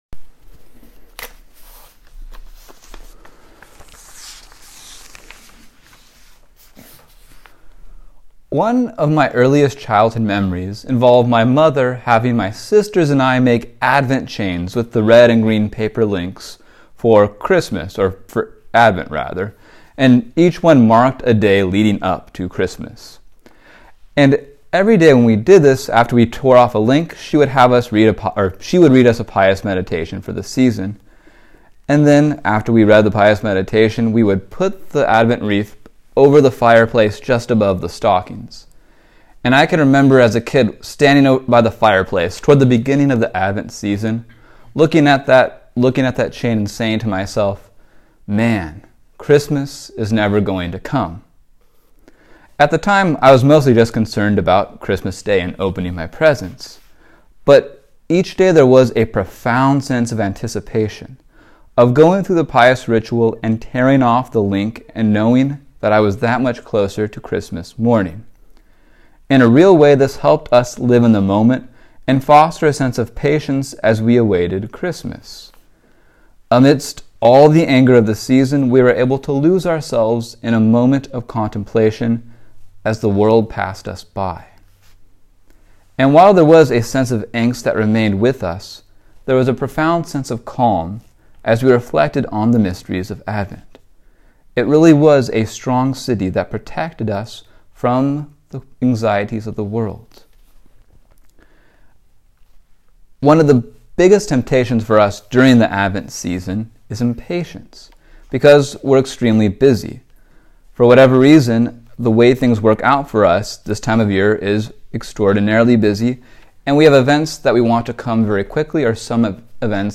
This homily was preached to the student brothers during compline.